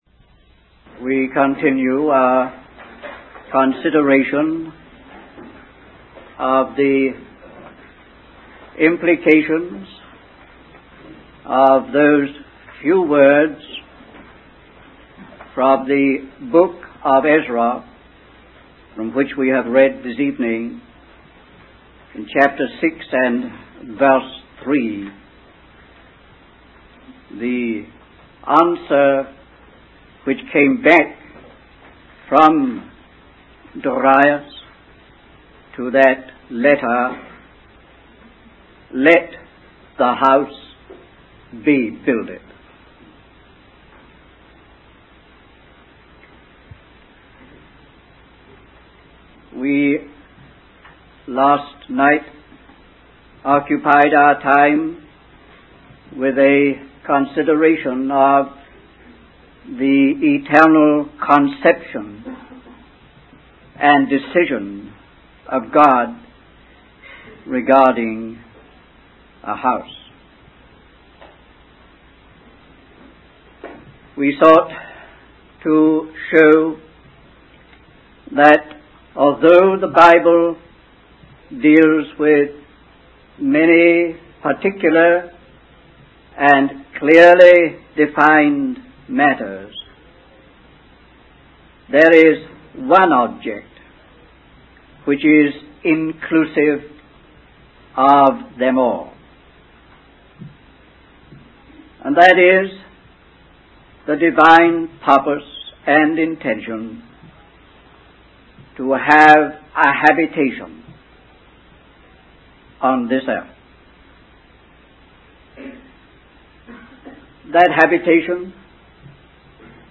In this sermon, the speaker emphasizes the importance of growing in our understanding of God's values and principles from the moment of our new birth. This growth is necessary to counter the works of the evil kingdom and for the church to become a powerful force.